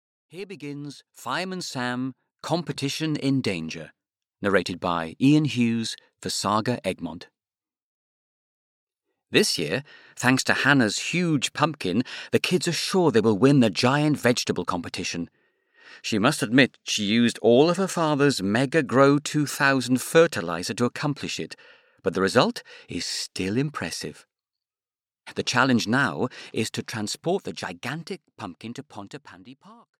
Fireman Sam - Competition in Danger (EN) audiokniha
Ukázka z knihy